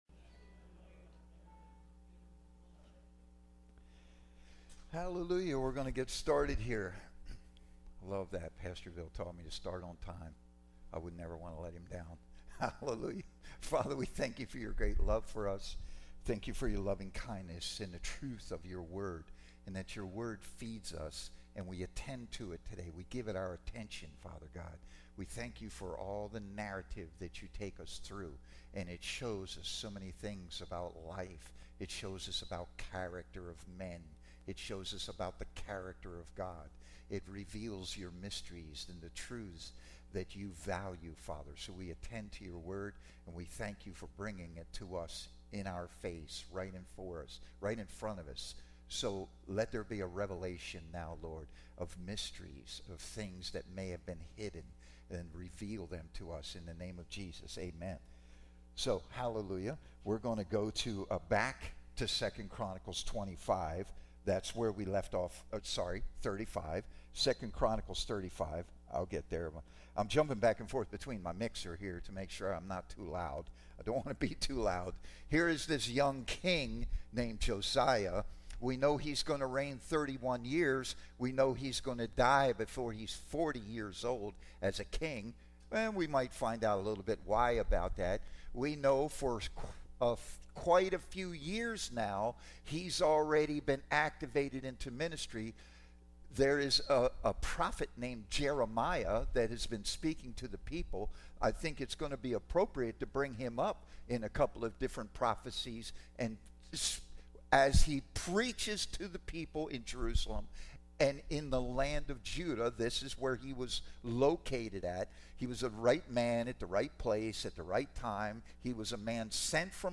Teaching Service